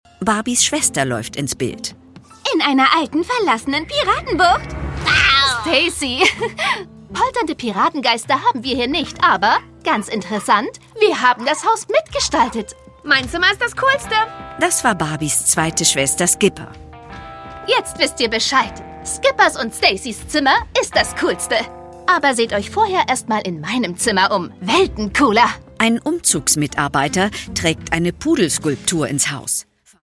Das Original-Hörspiel zur TV-Serie
Produkttyp: Hörspiel-Download